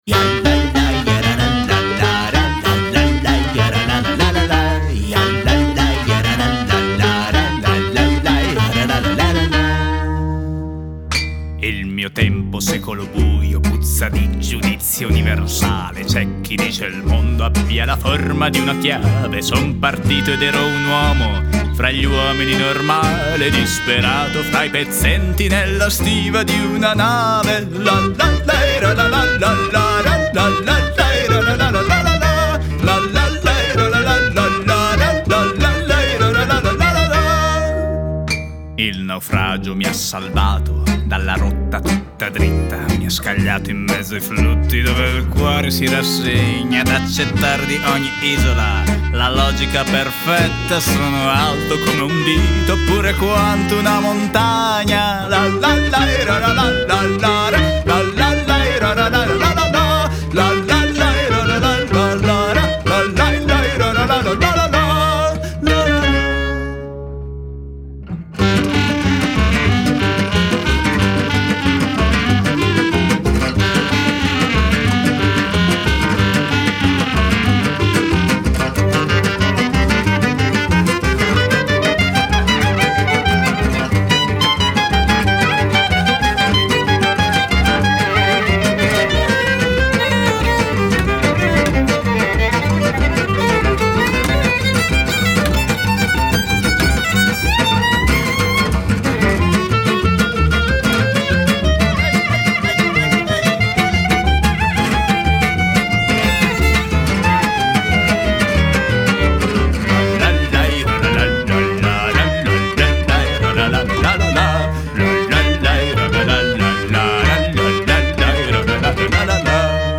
GenereWorld Music